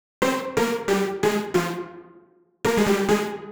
GA_SupiSyn136C-03.wav